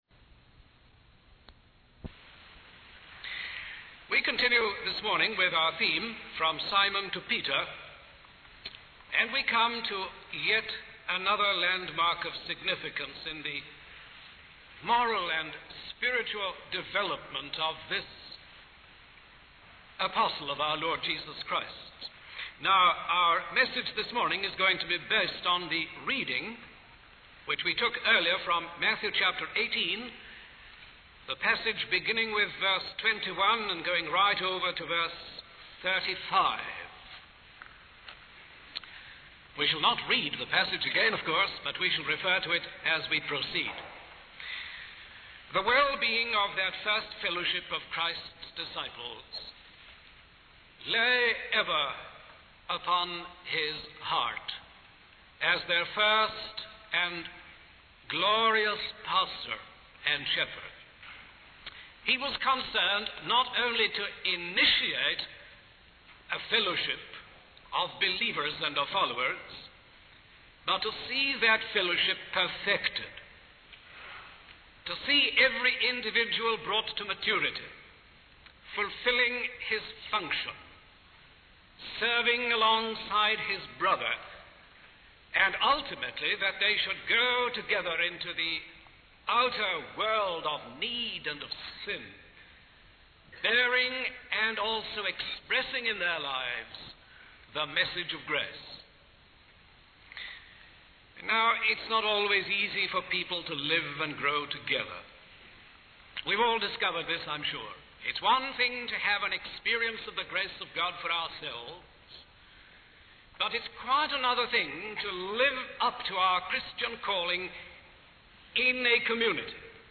In this sermon, the speaker tells a story about a man who owed a huge debt to a king.